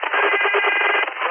Тон на 2130 кГц